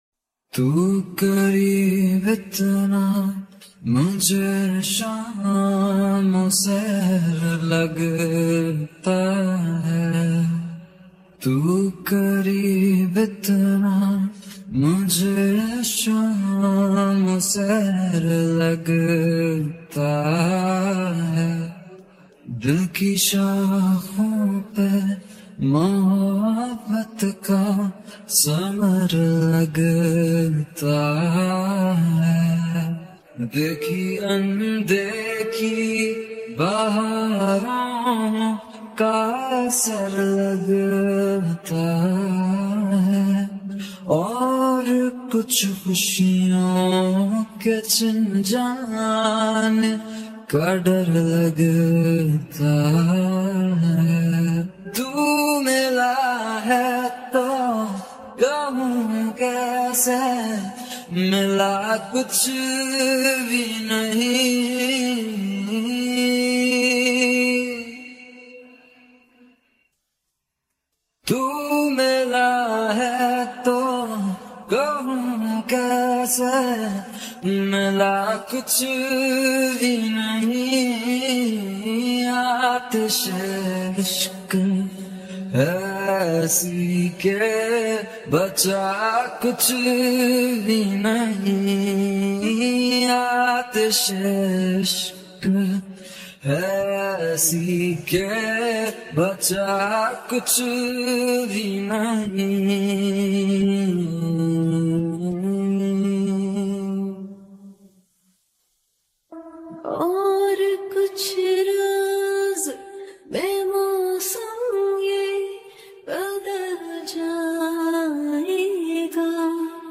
Vocals
Without Music